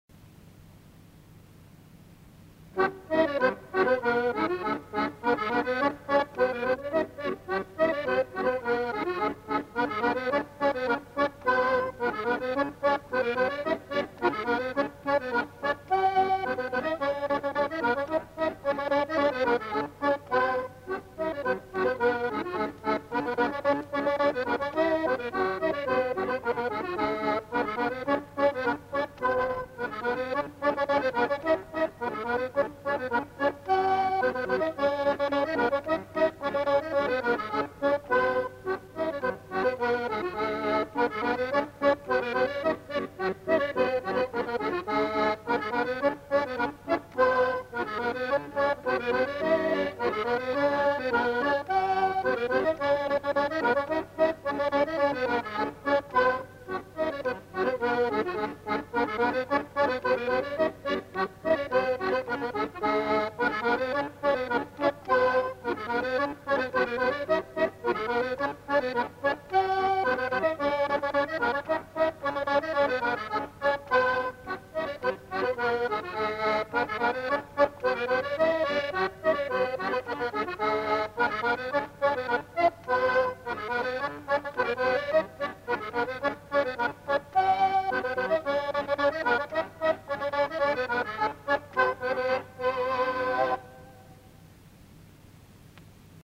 Lieu : Monclar d'Agenais
Genre : morceau instrumental
Instrument de musique : accordéon diatonique
Danse : scottish